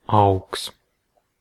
Ääntäminen
Synonyymit grow stick sow factory infiltrator implant Ääntäminen US NZ : IPA : /plɑːnt/ RP : IPA : /plɑːnt/ Australia: IPA : /plænt/ US : IPA : /plænt/ Canada: IPA : /plænt/ Northern England: IPA : /plænt/